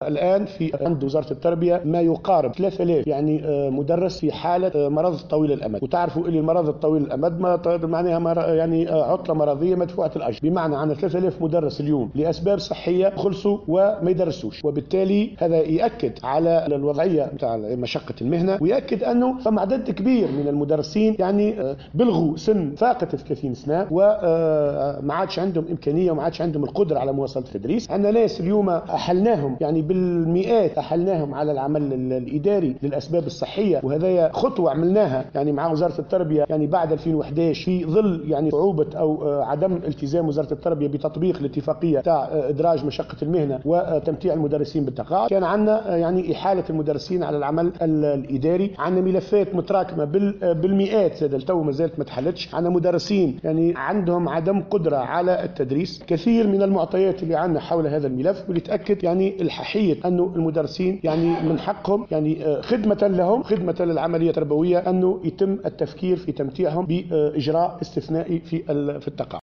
خلال جلسة استماع أمام لجنة التربية بالبرلمان